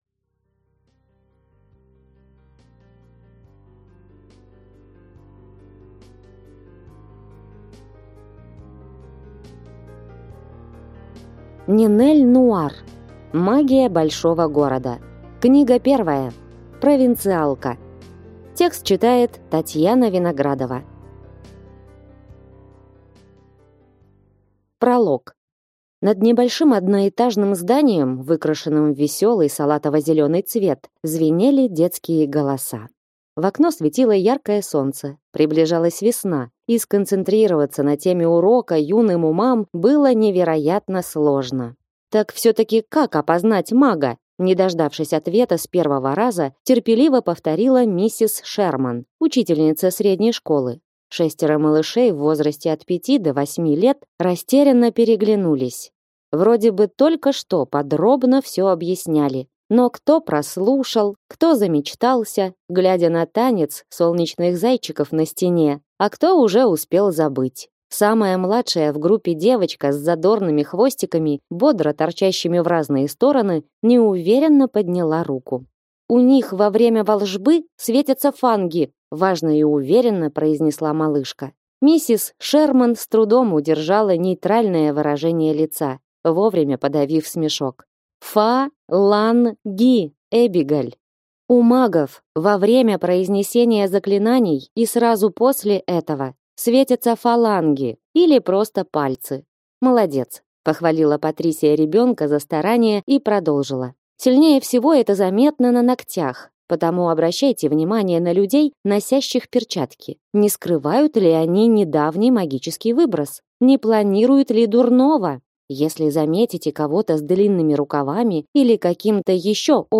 Аудиокнига Магия большого города. Книга 1. Провинциалка | Библиотека аудиокниг
Прослушать и бесплатно скачать фрагмент аудиокниги